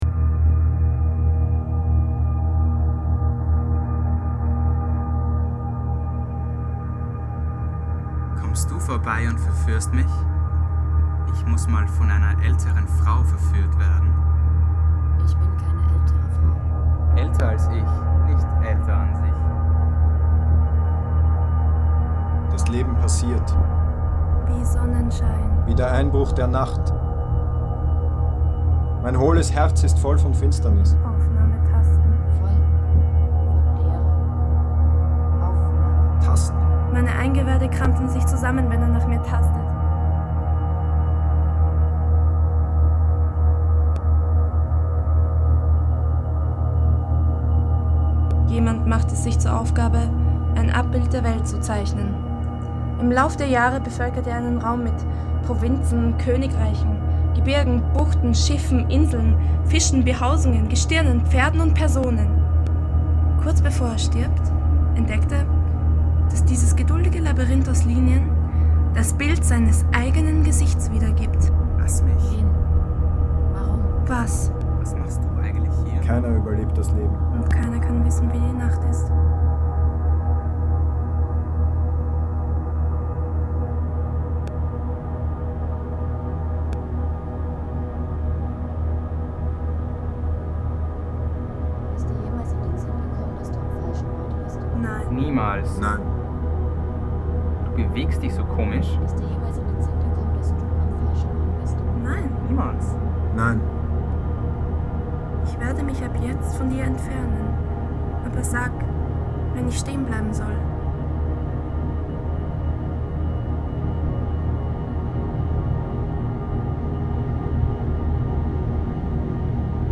site specific spatial sound composition